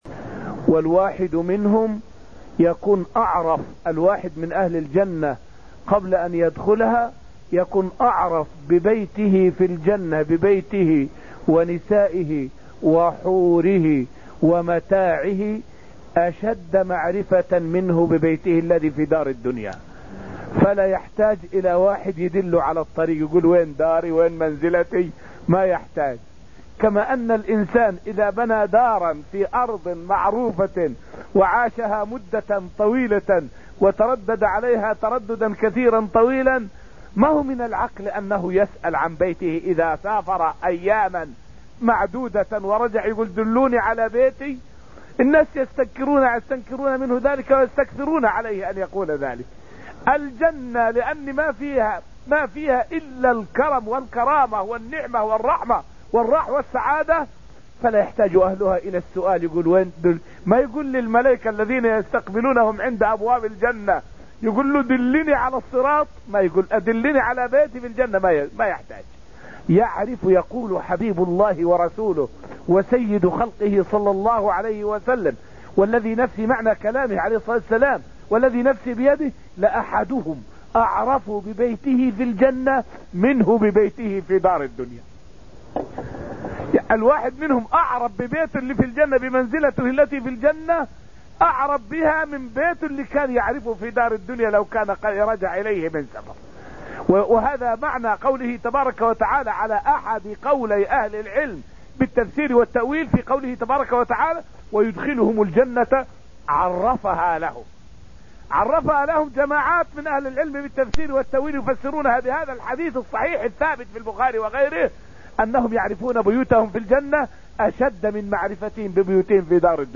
فائدة من الدرس الرابع عشر من دروس تفسير سورة الحديد والتي ألقيت في المسجد النبوي الشريف حول معرفة أهل الجنة لبيوتهم فيها.